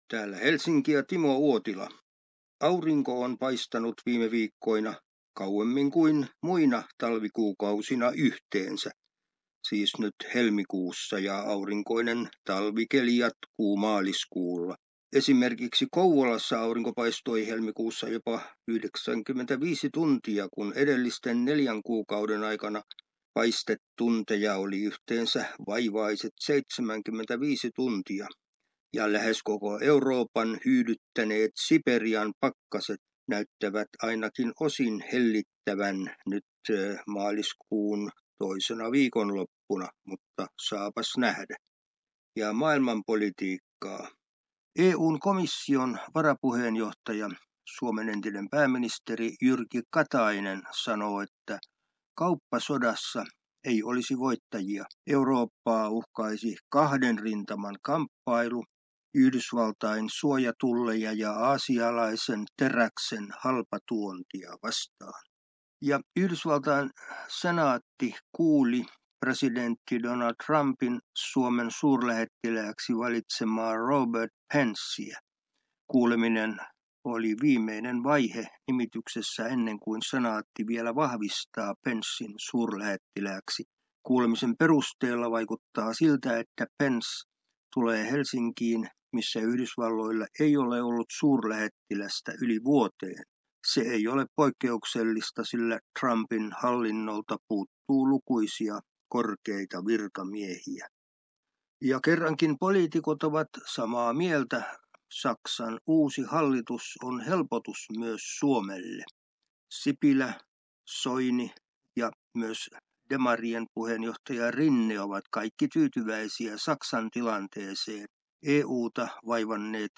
ajankohtaisraportti